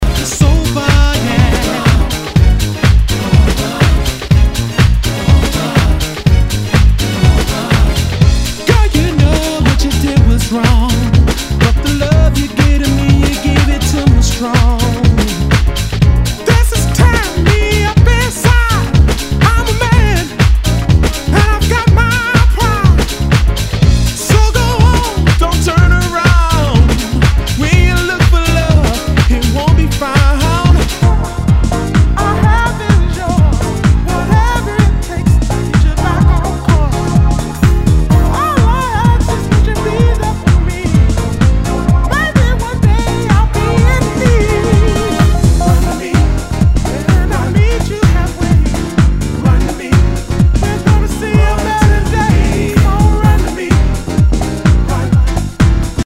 HOUSE/TECHNO/ELECTRO
ナイス！ディープ・ヴォーカル・ハウス！